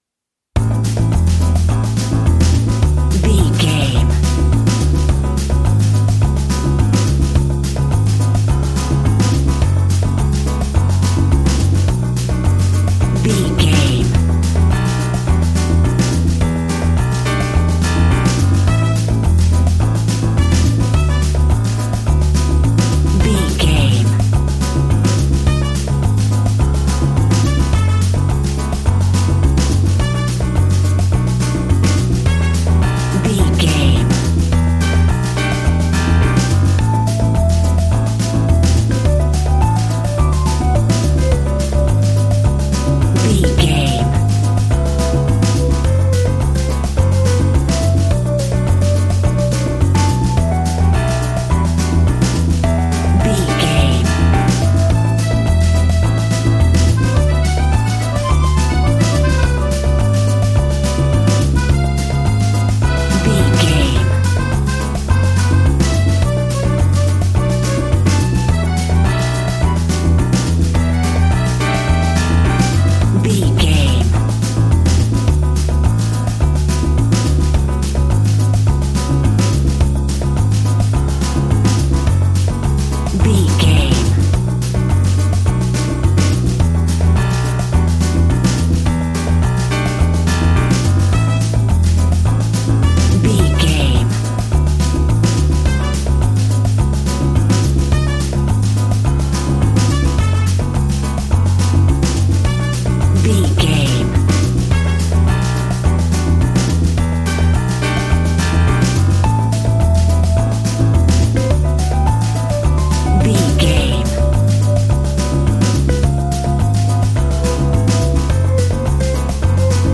An exotic and colorful piece of Espanic and Latin music.
Aeolian/Minor
flamenco
maracas
percussion spanish guitar